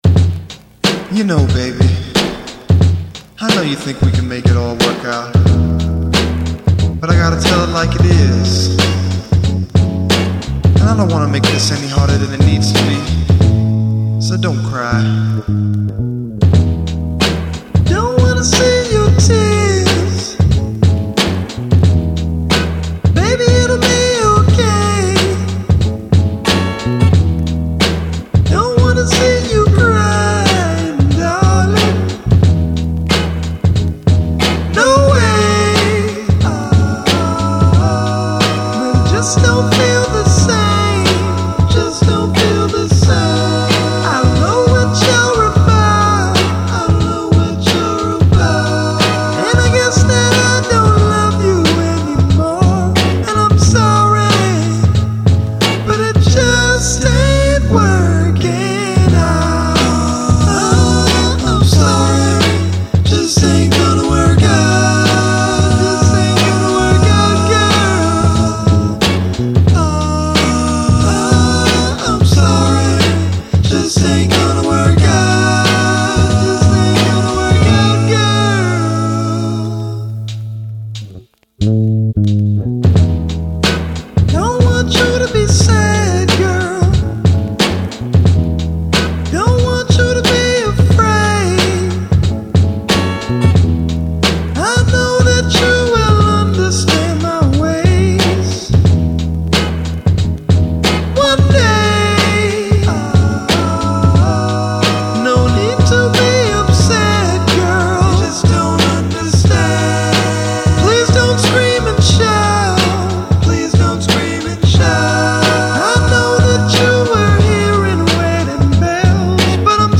He wrote, played, recorded and sang on these tracks.